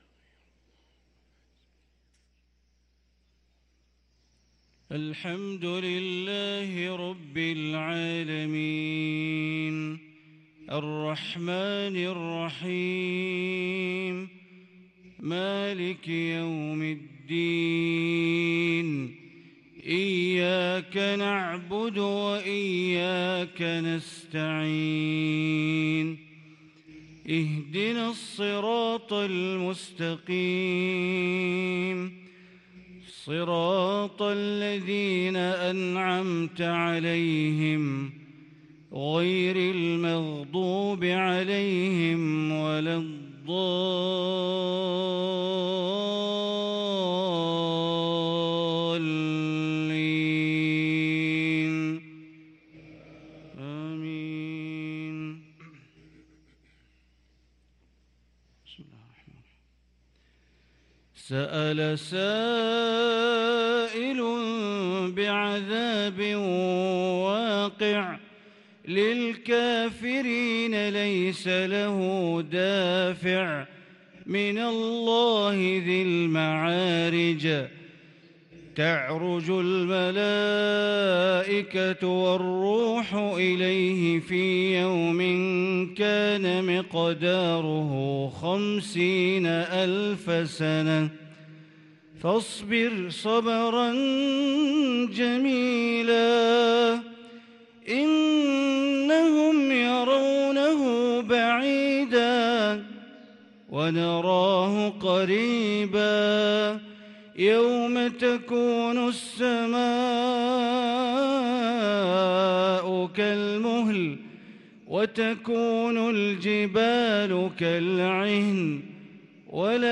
صلاة الفجر للقارئ بندر بليلة 11 ربيع الآخر 1444 هـ